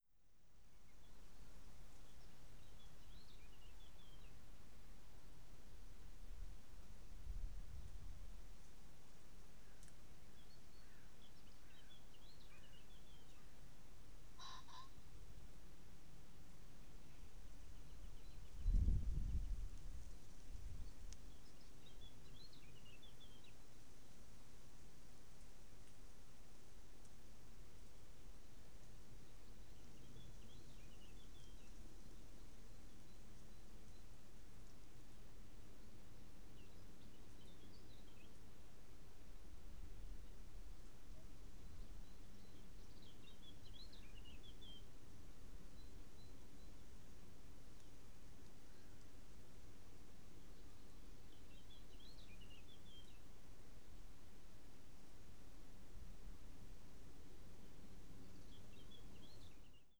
Audiobeispiel 4 vom 05.05.2019 05:10, Oberrheinische Tiefebene, südlich von Freiburg, kleine Waldinsel.